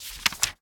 bookturn1.ogg